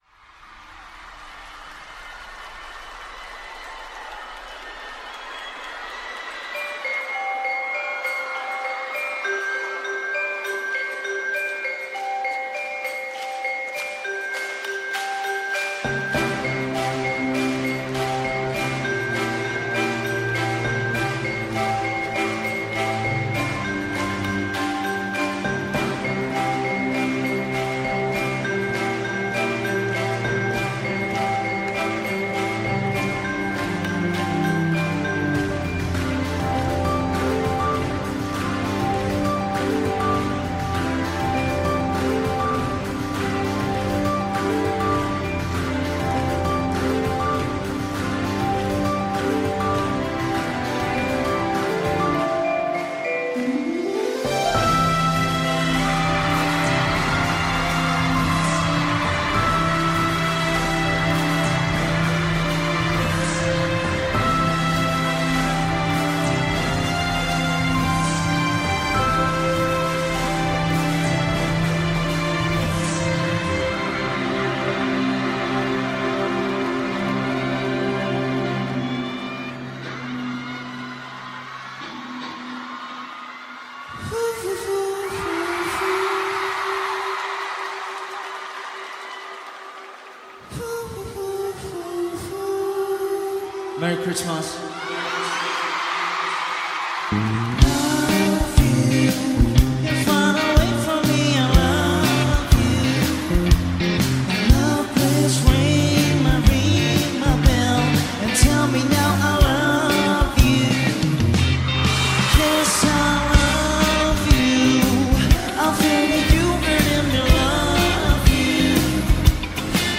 Indie From South Korea